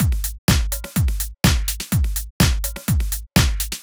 Drumloop 125bpm 04-A.wav